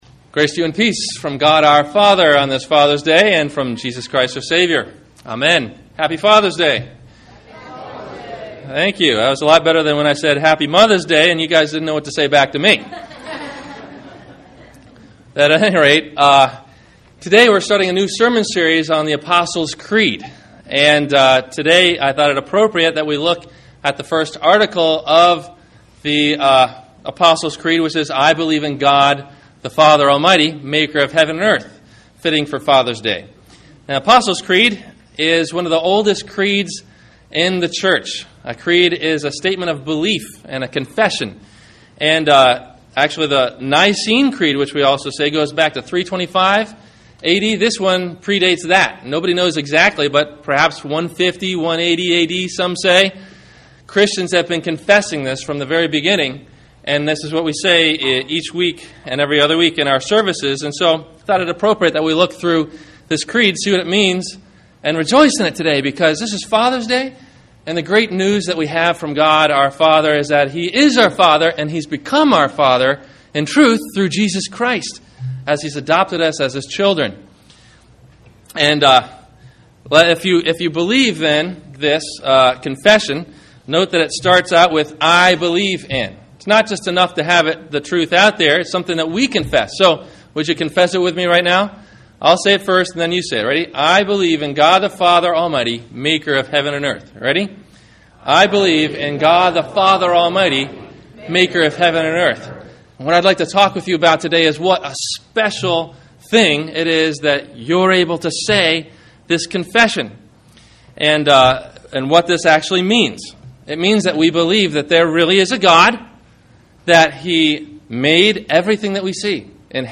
The Apostles Creed – God The Father – Sermon – June 21 2009